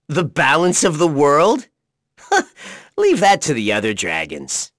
Neraxis-vox-dia_03.wav